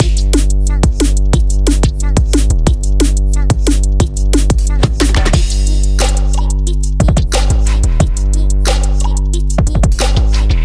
ドラムンベース→トラップビート
こんなふうに、事実上スピード感が半減したドラム演奏を同じテンポの枠内に収めることができるので、あるパートで勢いをゆっくりに変えたり、ドラムンベースとトラップのような毛色の違ったリズムパターンを一曲の中に入れ込むことができるわけです。
r1-time-normal-to-half-trap.mp3